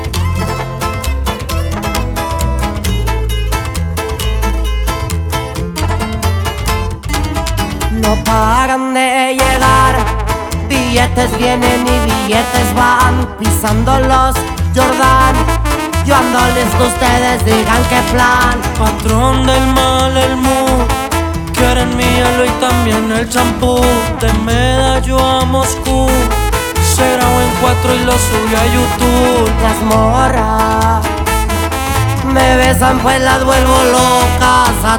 # Música Mexicana